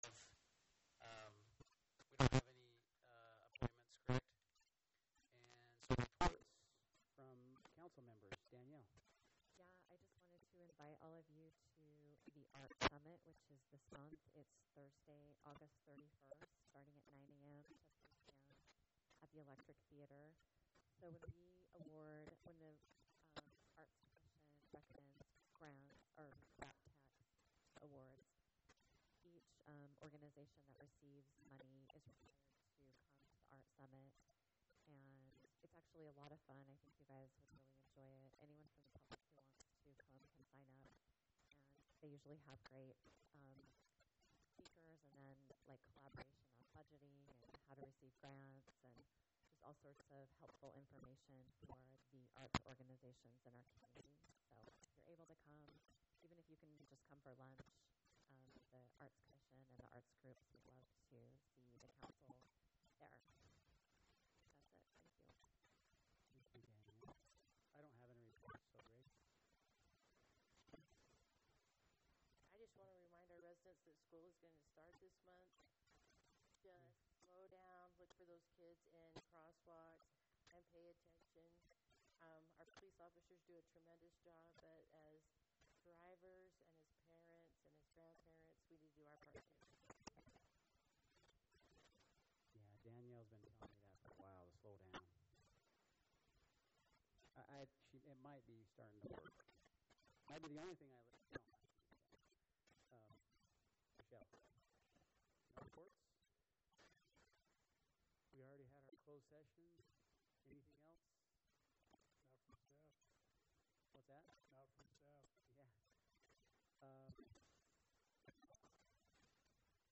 City Council Meeting Agenda
Notice, Meeting, Hearing